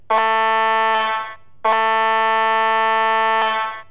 Soundcards for Light Industrial Locomotives in 16mm, G scale, Gauges 1 and 3 and ride on railways using 100% recorded sounds.
light_horn_whitcomb.wav